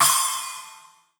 S_splash1_1.wav